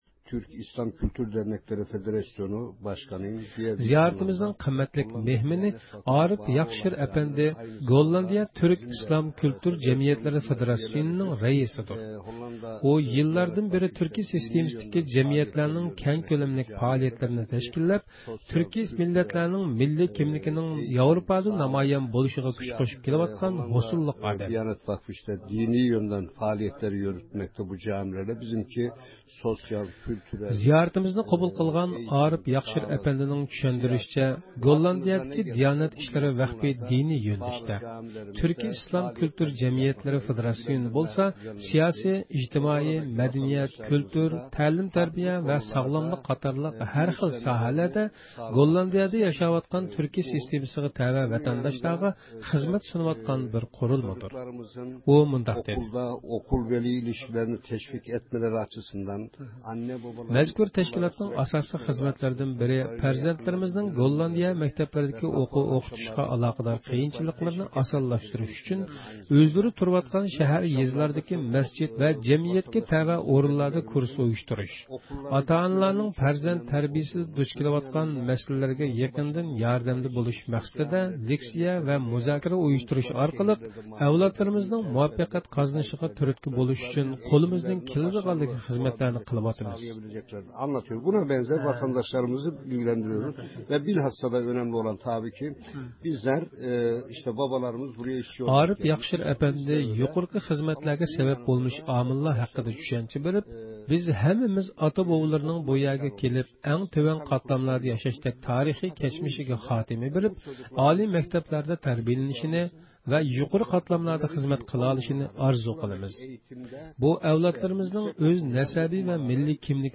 رادىئويىمىزنىڭ زىيارىتىنى قوبۇل قىلىپ